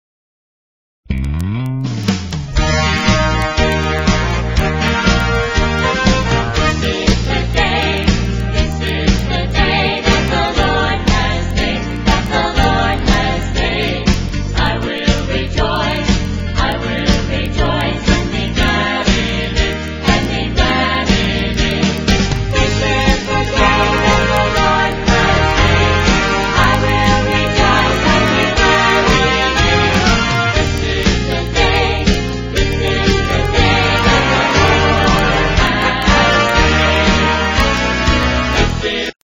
4 tracks WITH GUIDE VOCALS